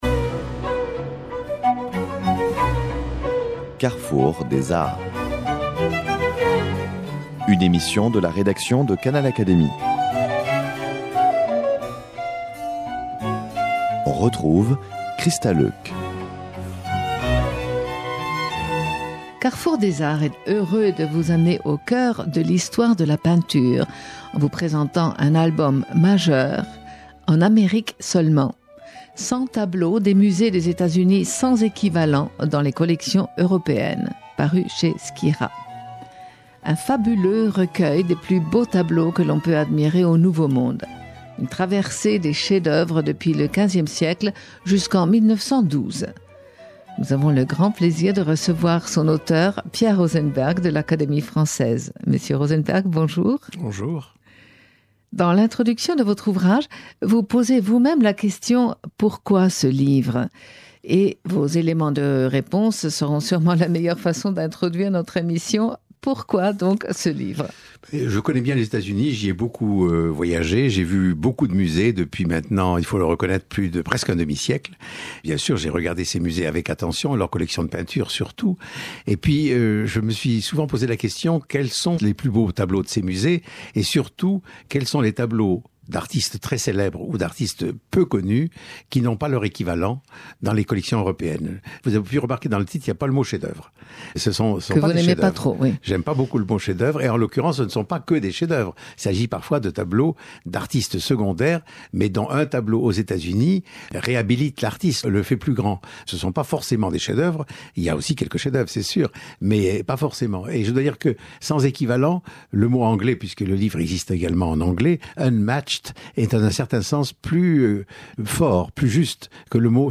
Pierre Rosenberg, de l’Académie française, a publié un recueil En Amérique seulement, cent tableaux des musées des États-Unis, sans équivalent dans les collections européennes. Il le présente ici, invité à Canal Académie